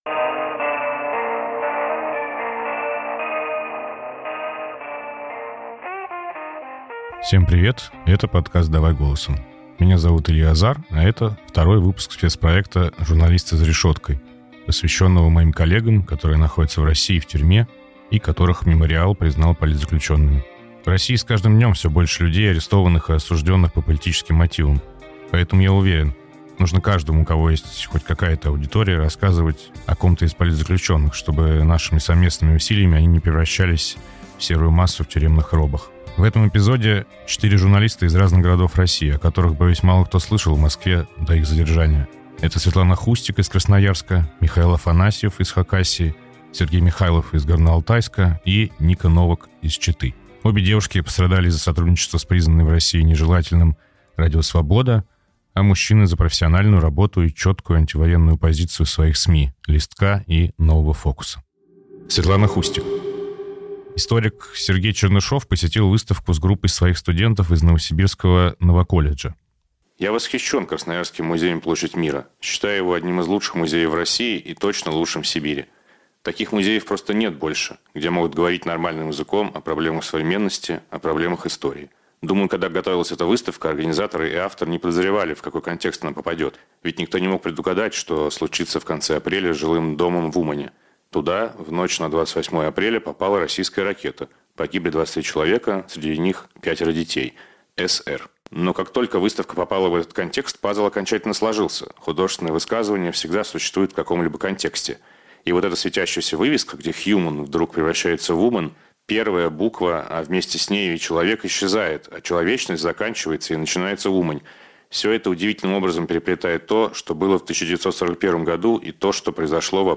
Подкаст ведёт Илья Азар